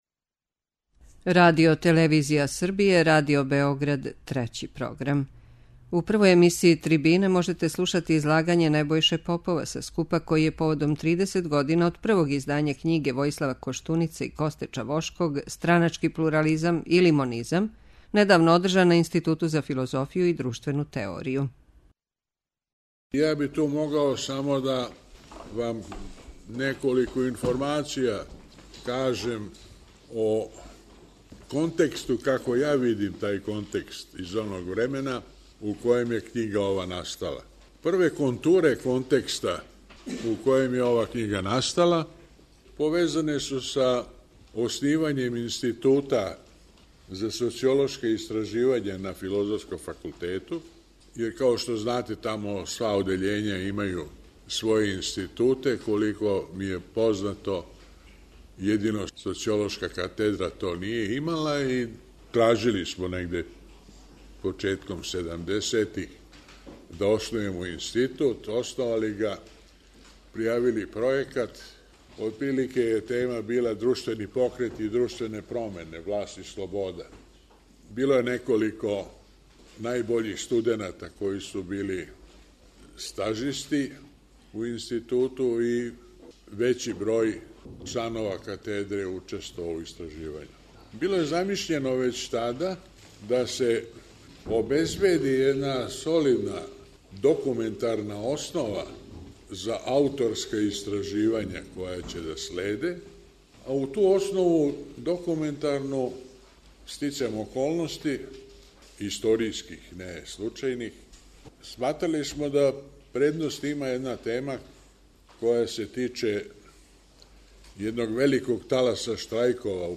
У две емисије ТРИБИНЕ можете слушати излагања са скупа који је поводом тридесетогодишњице првог издања књиге Војислава Коштунице и Косте Чавошког 'Страначки плурализам или монизам' недавно одржан на Институту за филозофију и друштвену теорију.
Трибине